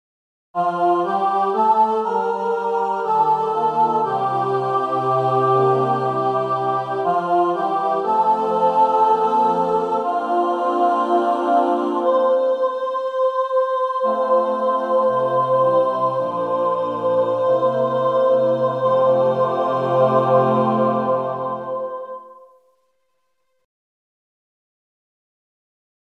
Key written in: F Major
Other part 2: